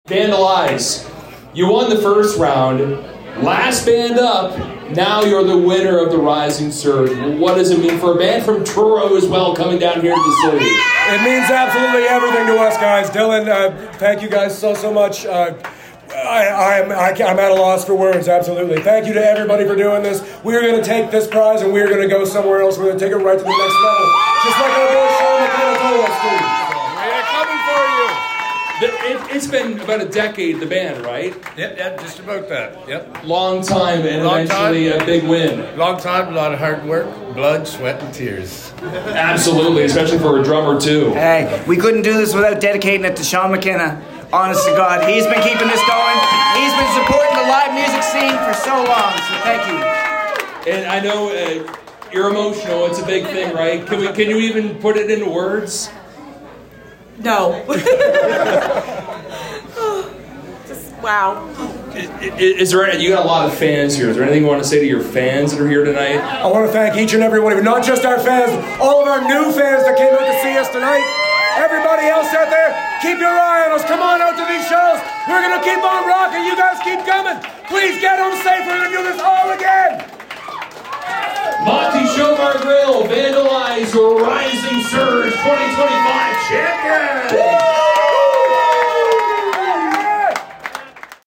A BIG NIGHT AT MONTES!